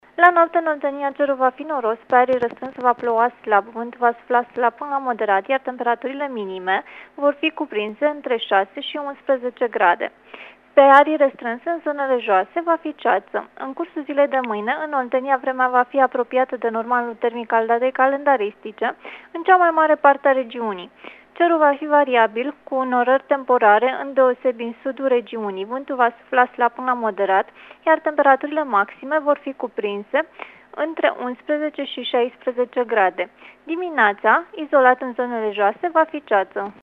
Prognoza meteo 7/8 noiembrie (audio)